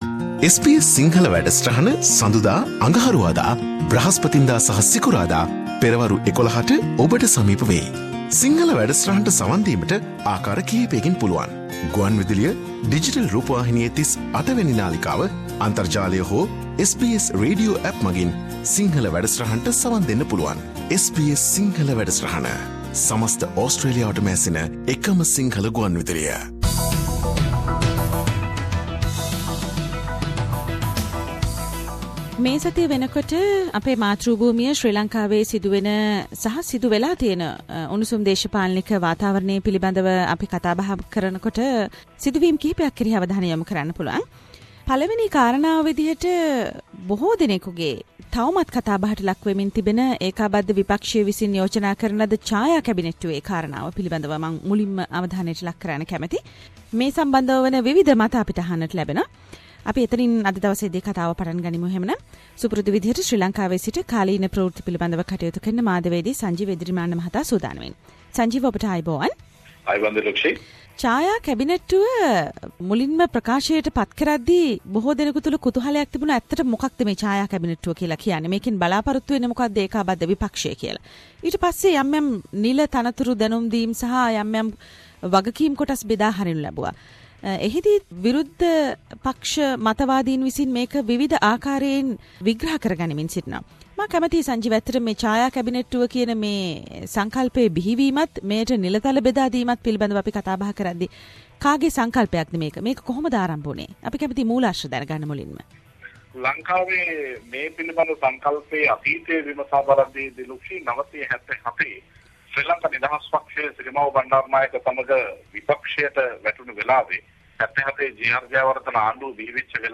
Weekly Sri Lsankan news wrap